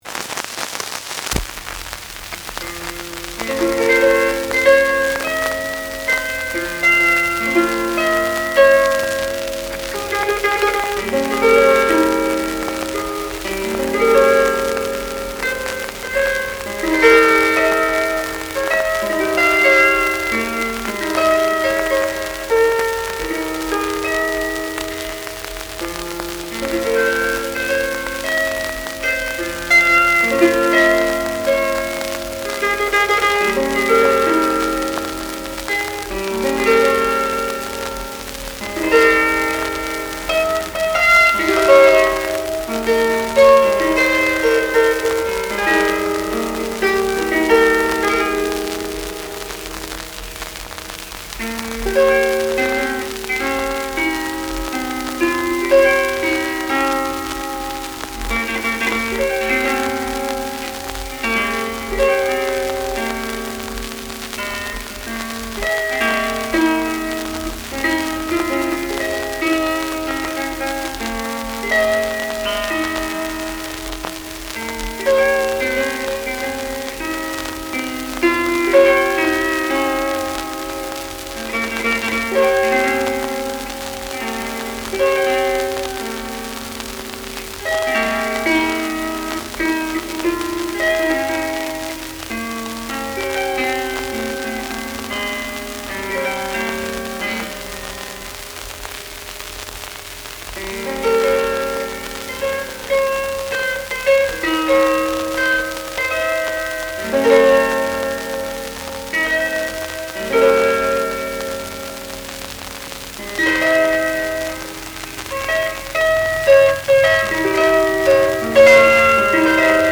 I have transferred them using a hifi turntable and lightweight pickup.
on the Bardic Harp